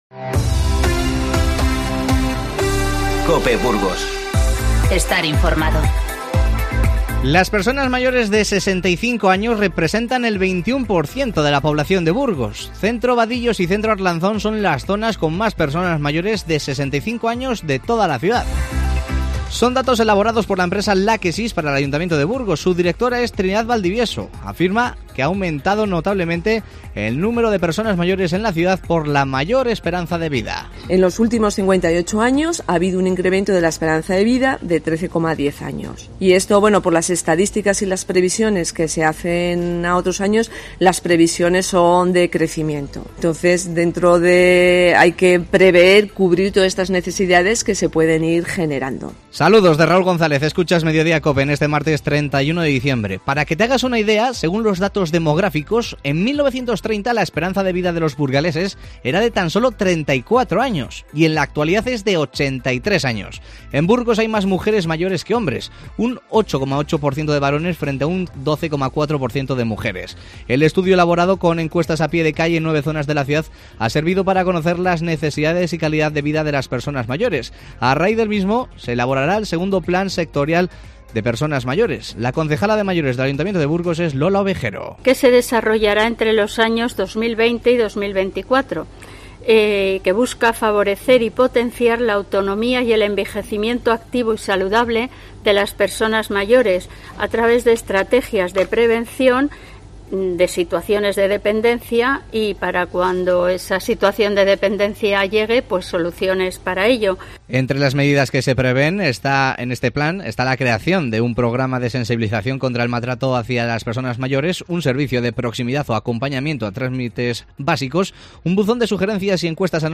INFORMATIVO Mediodía 31-12-19